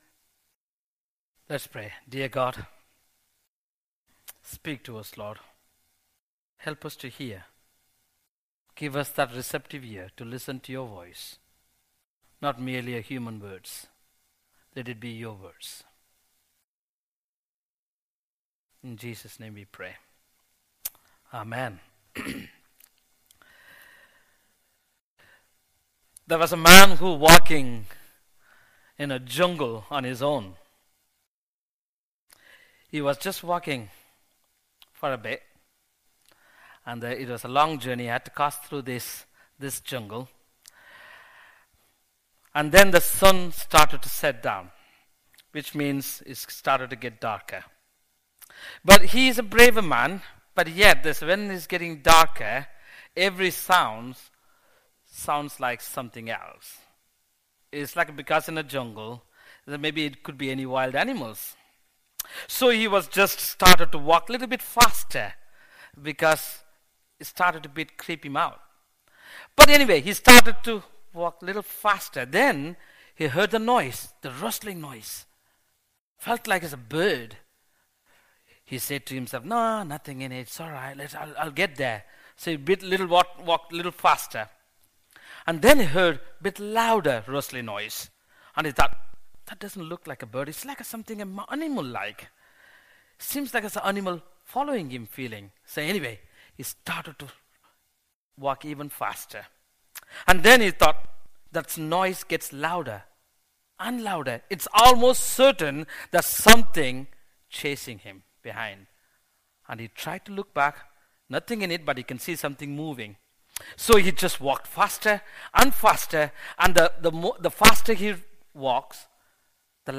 An audio version of the sermon is also available.
09-15-sermon.mp3